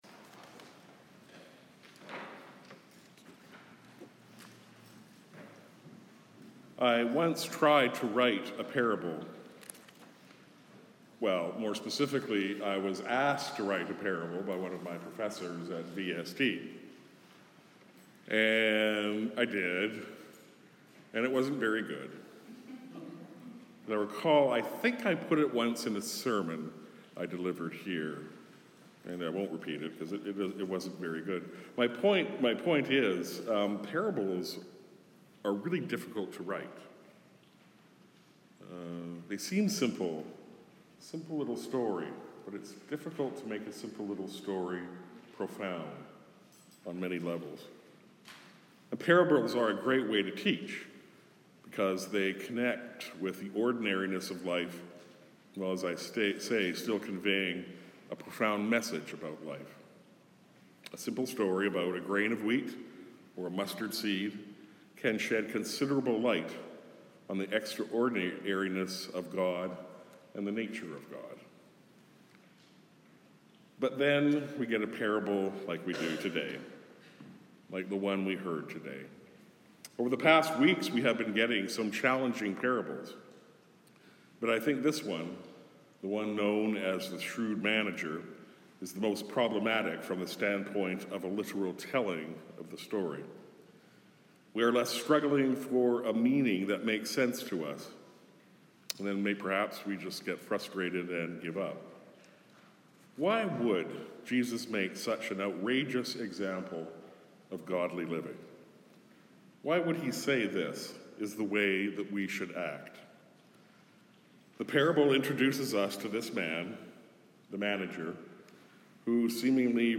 Sermons | St. John the Evangelist